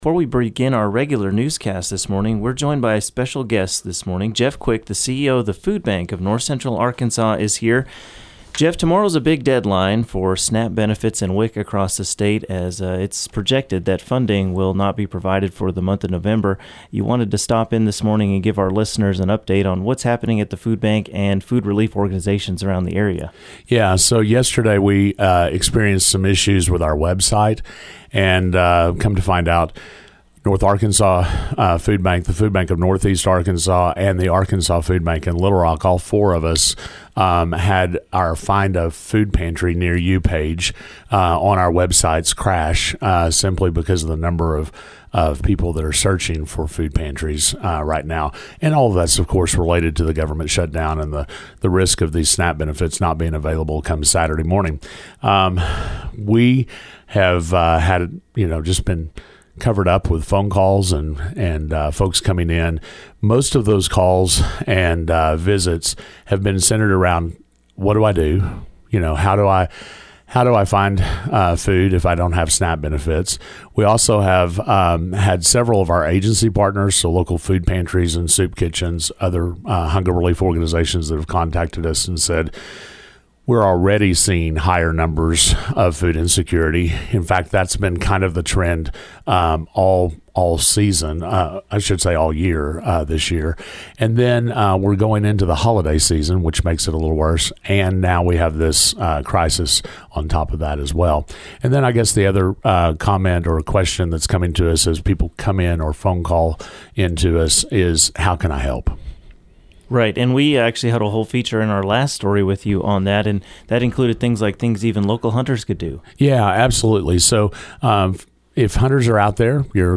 Live at KTLO with Food Bank CEO Thursday morning